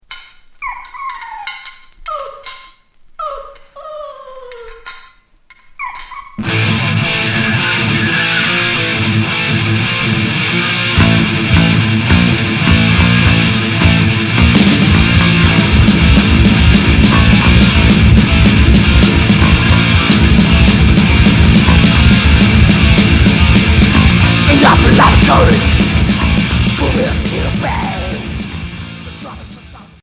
Trash métal